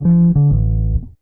BASS 14.wav